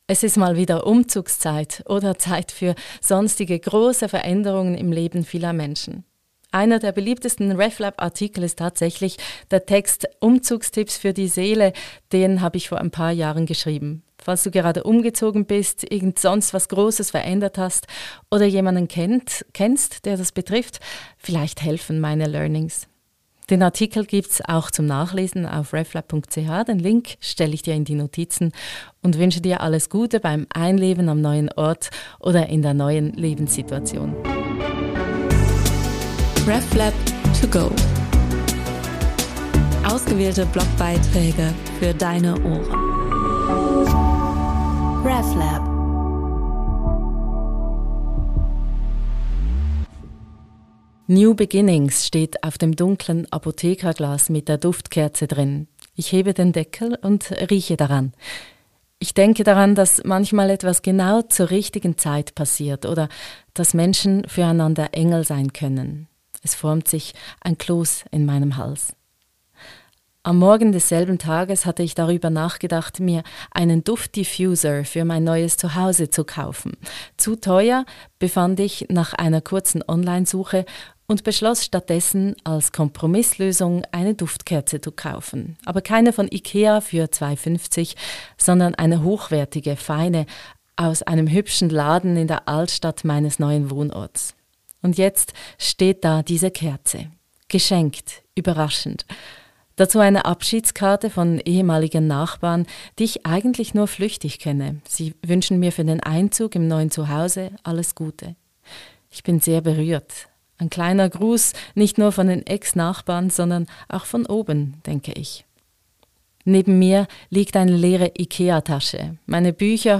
Meine Learnings – für Umzüge und andere Veränderungen. Geschrieben